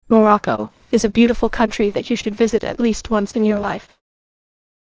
voice-cloning-AI - Voice cloning AI (deepfake for voice). Using cloned voice from only 5-10 seconds of targeted voice.